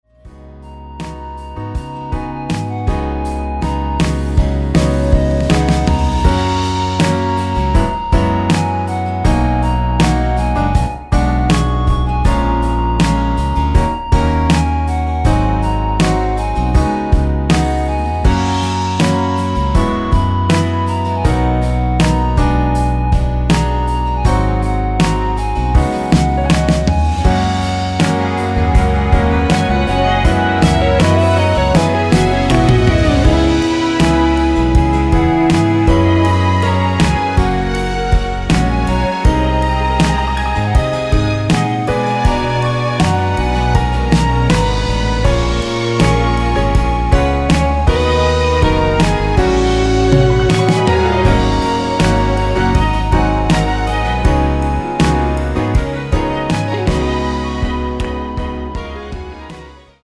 엔딩이 페이드 아웃이라 라이브 하시기 좋게 엔딩을 만들어 놓았습니다.
원키에서(+2)올린 멜로디 포함된 2절 삭제 MR 입니다.(미리듣기및 아래의 가사 참조)
F#
노래방에서 노래를 부르실때 노래 부분에 가이드 멜로디가 따라 나와서
앞부분30초, 뒷부분30초씩 편집해서 올려 드리고 있습니다.
중간에 음이 끈어지고 다시 나오는 이유는